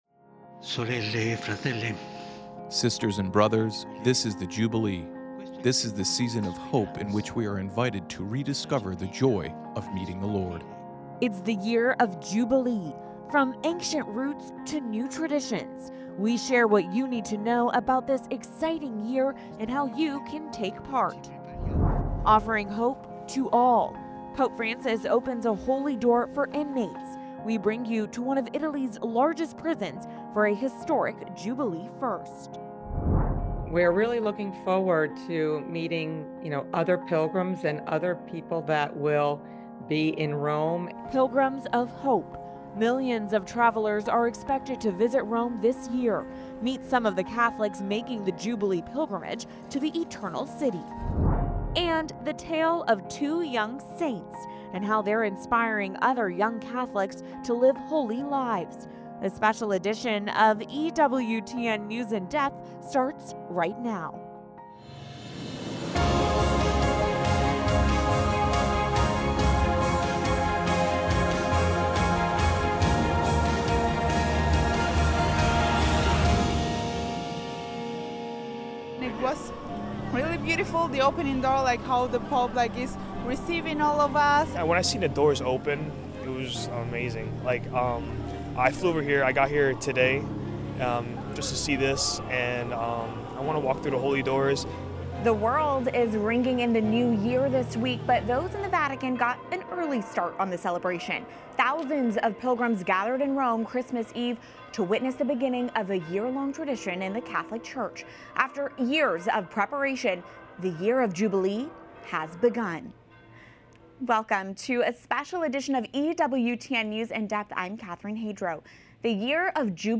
EWTN’s weekly one-hour discussion of current events in the Church, politics, and culture, from a Catholic perspective. Covering the underreported stories and clarifying the Church's position on key teachings, with a focus on the laity's needs and concerns.